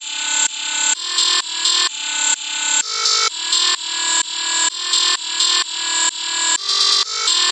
电子低音
描述：电子低音与其他两个循环相配合。
Tag: 128 bpm Electro Loops Synth Loops 1.27 MB wav Key : A